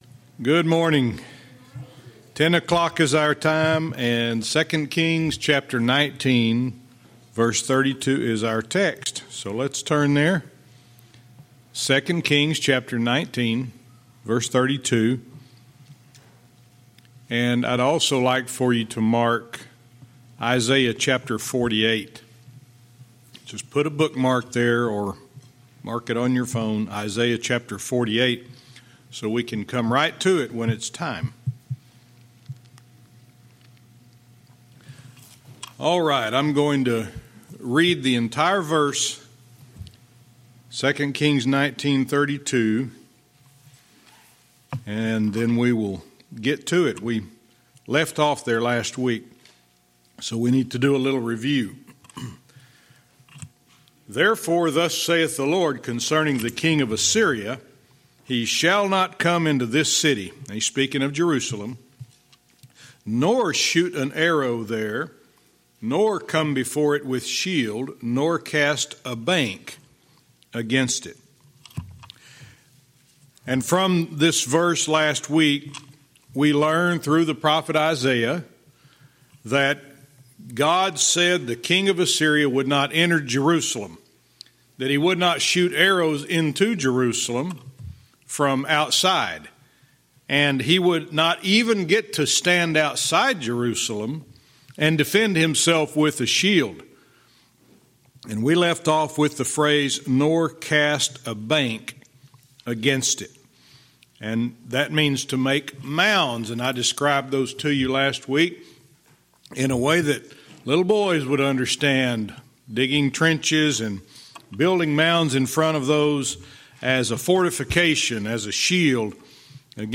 Verse by verse teaching - 2 Kings 19:32-34